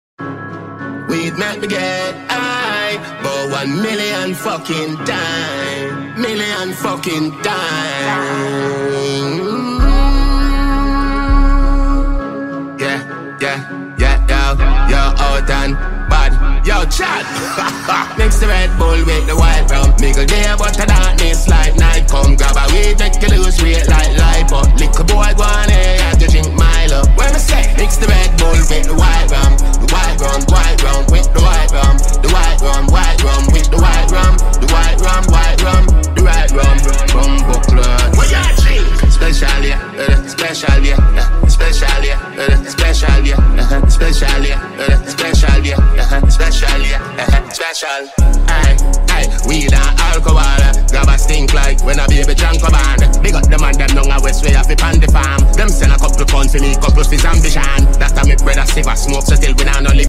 now dancehall nice again